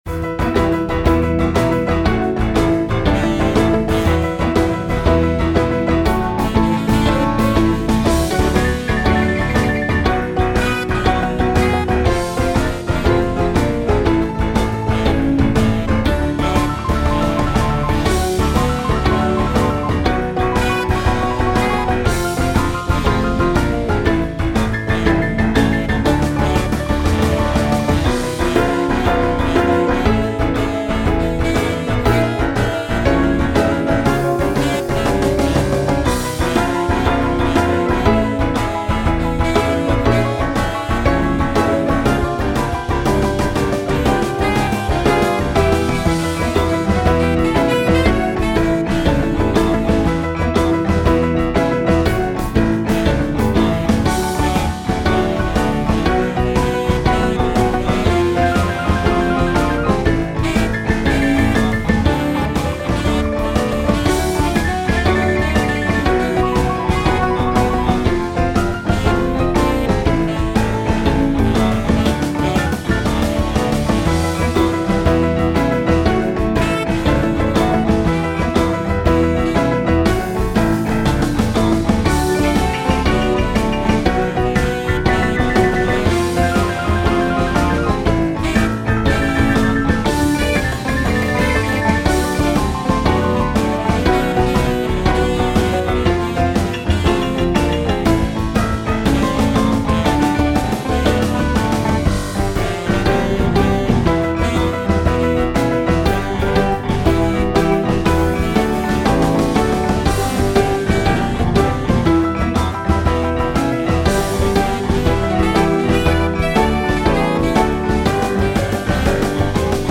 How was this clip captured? midi-demo 2 midi-demo 3